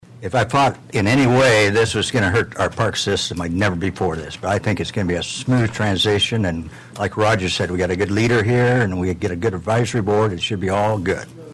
The Council’s vote on Wednesday followed a Public Hearing on the issue on August 2. Councilman Pat McCurdy motioned to dissolve the Board on the first reading.